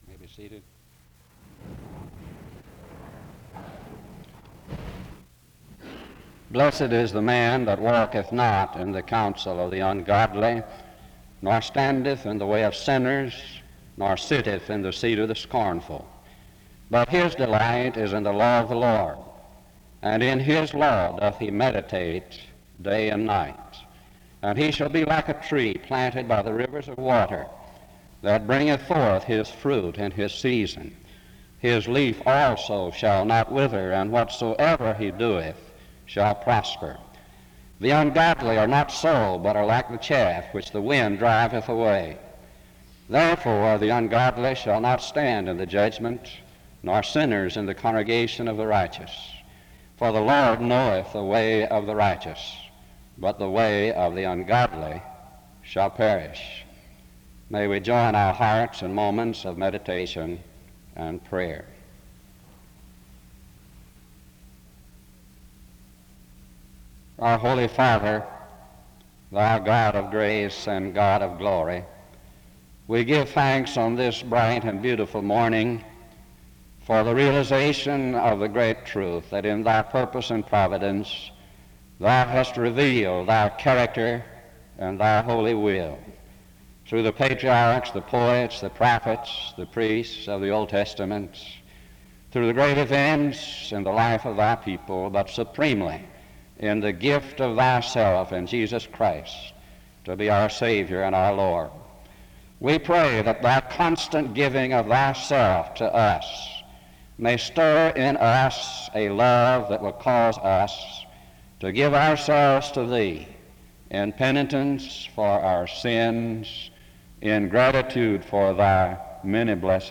The service opens in a word of prayer from 0:00-3:14.
SEBTS Chapel and Special Event Recordings SEBTS Chapel and Special Event Recordings